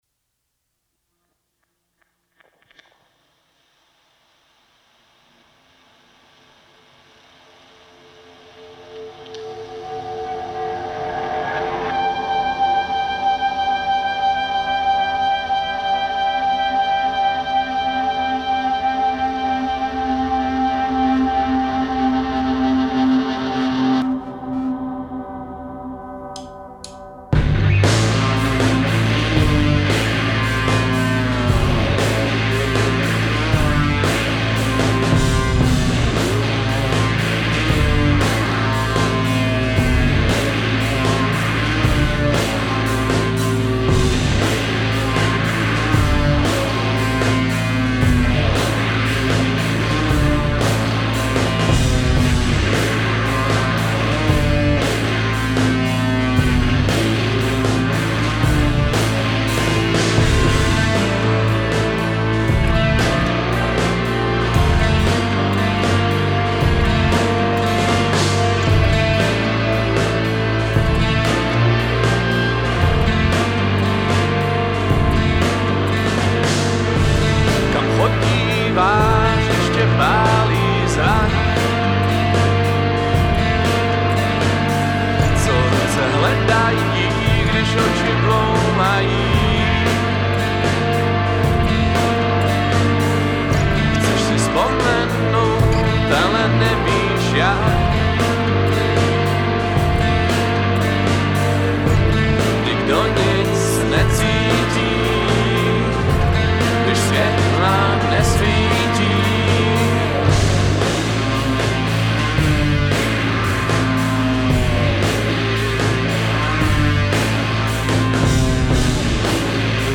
kytarovým nástupem na pomezí hard rocku a grunge